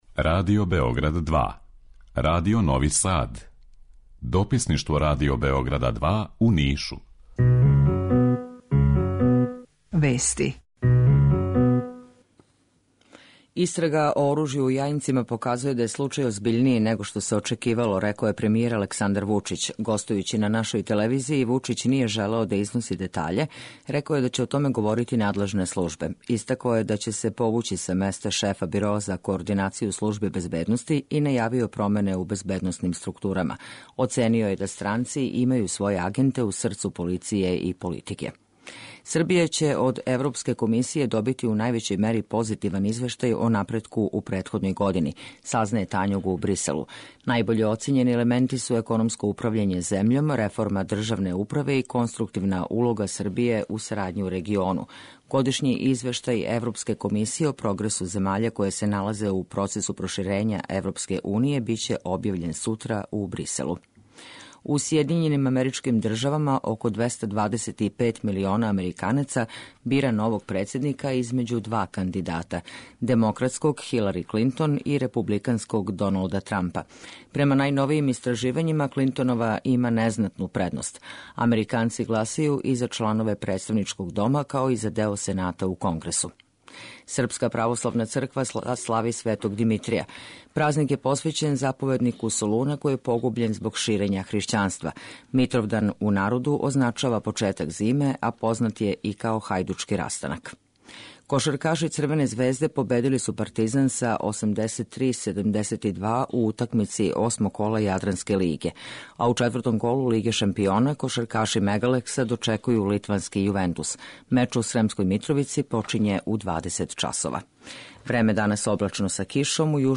У два сата ту је и добре музике, другачија у односу на остале радио-станице.